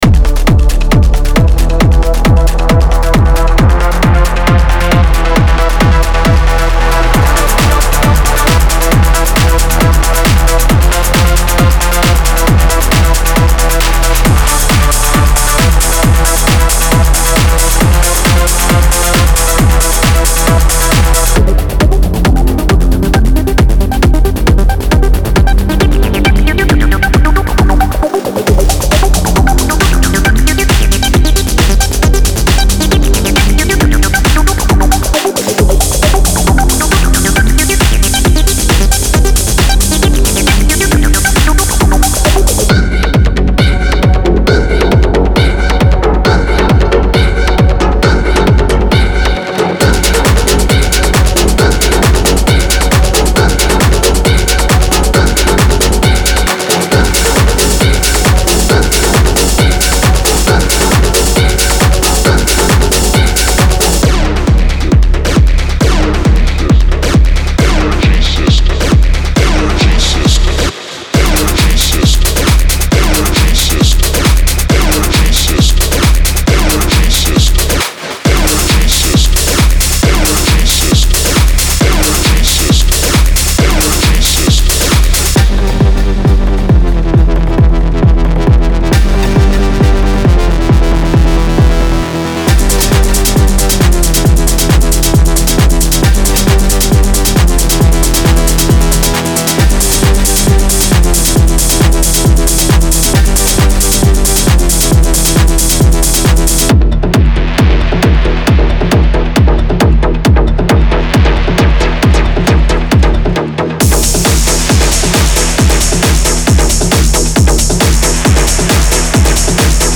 – Loops 135 BPM & Key Labeled
MP3 DEMO